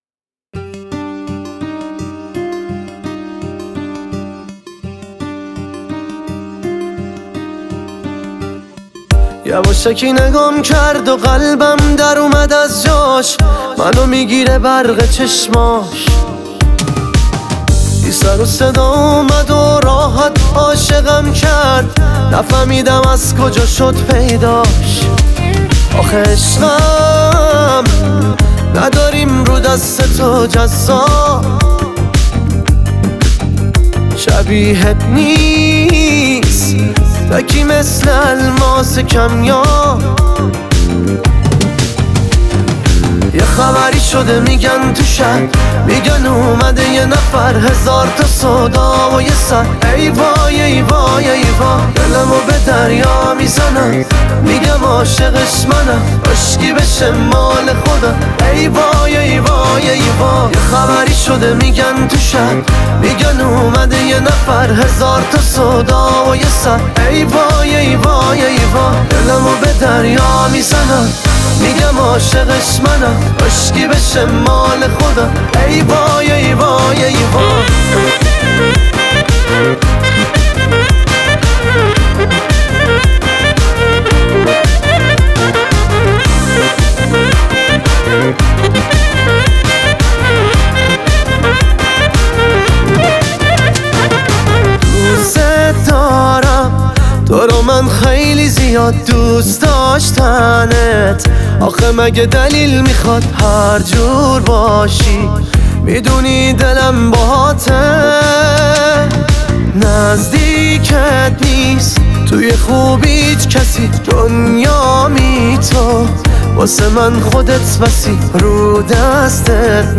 دانلود آهنگ شاد عروسی